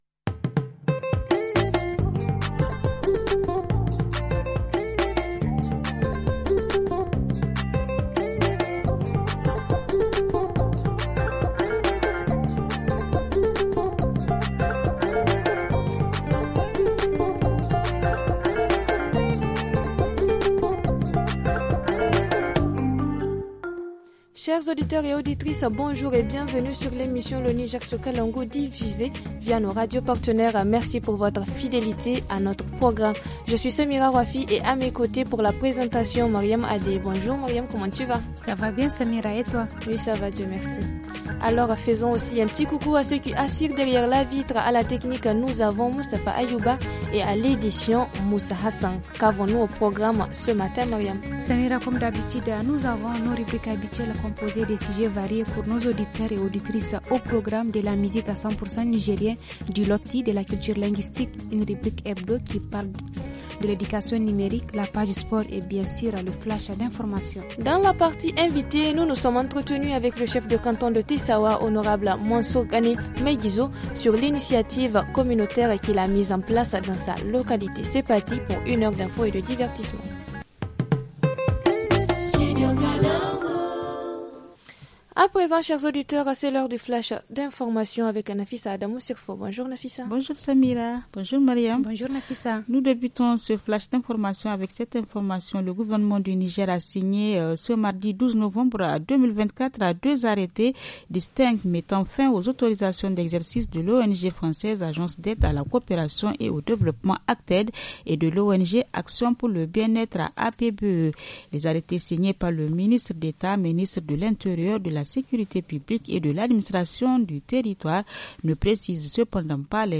Dans l’émission de ce jeudi : entretien, avec le chef de canton de Tessaoua, l'Honorable Mansour Kané Maiguizo